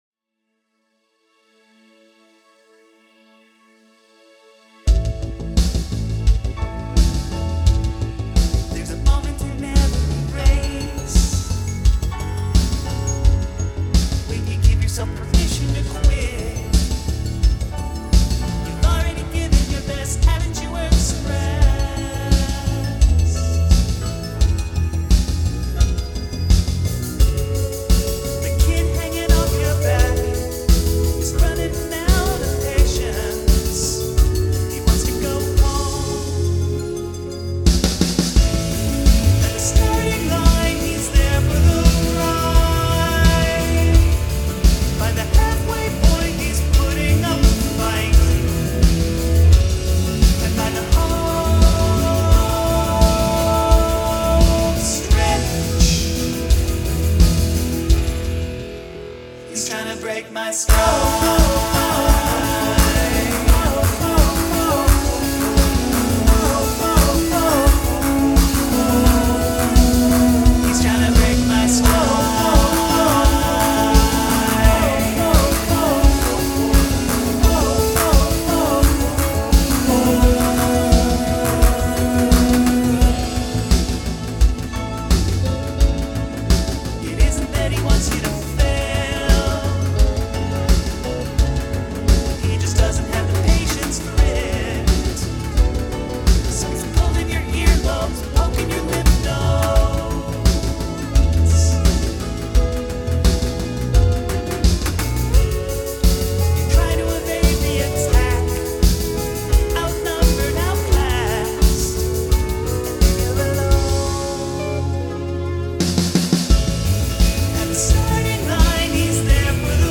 Polyrhythm
I thought the bass lacked some definition as well.